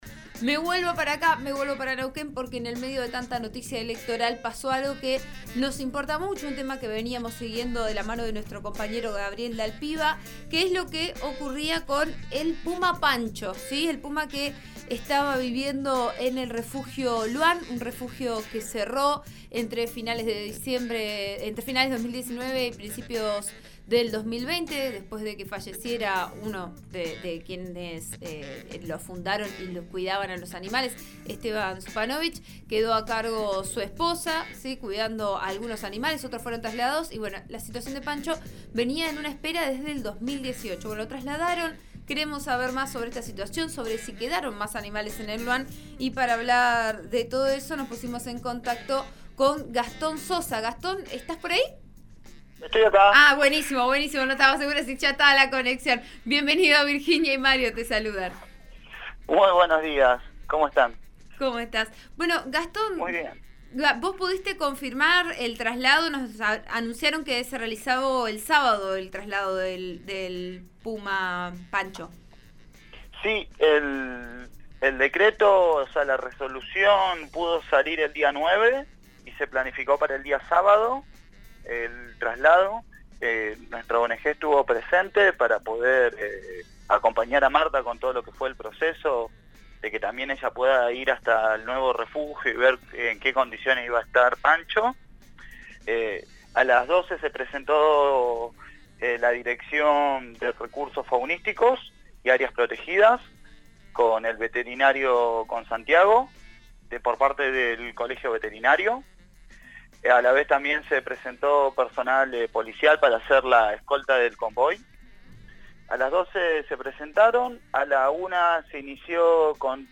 de la organización «Perros Perdidos» dialogó en el programa Vos a Diario, de RN RADIO (89.3) y detalló que la resolución que tanto esperaban pudo salir el 9 de septiembre y el traslado se pudo efectuar el pasado sábado.